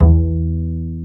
Index of /90_sSampleCDs/Roland - String Master Series/STR_Cb Pizzicato/STR_Cb Pizz 2